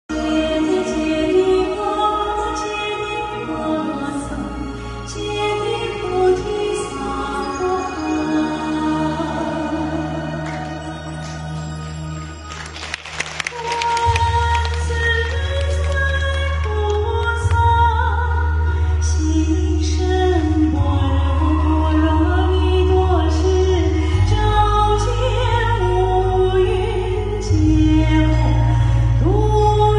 心经》！日本东京观音堂共修组活动（1）2021年12月05日！